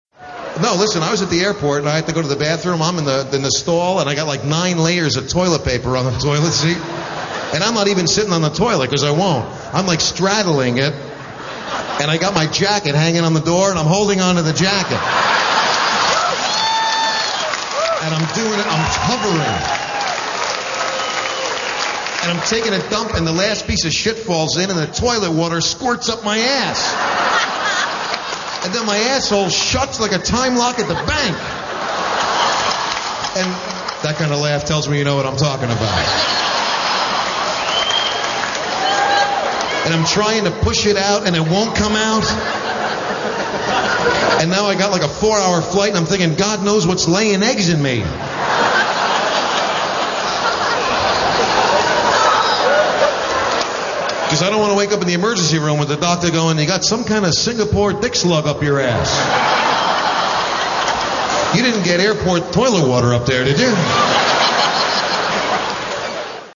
Category: Comedians   Right: Personal
Tags: Comedian Robert Schimmel clips Robert Schimmel audio Stand-up comedian Robert Schimmel